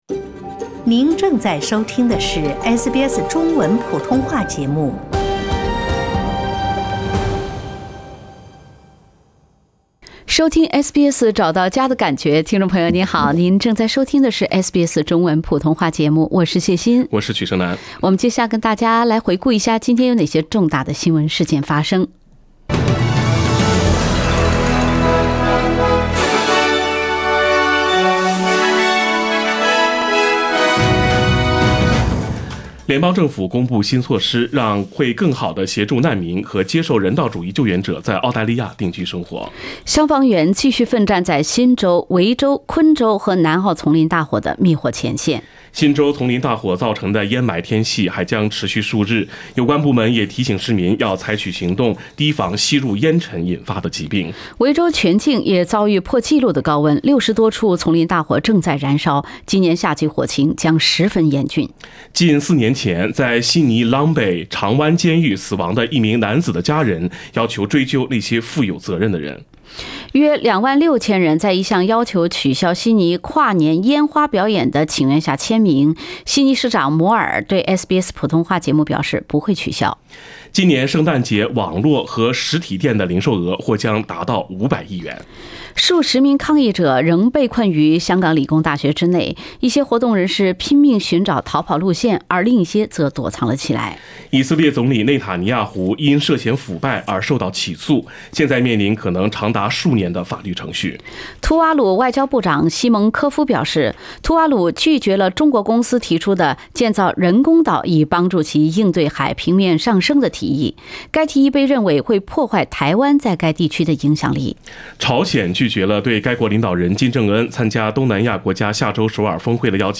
SBS早新闻 （11月22日）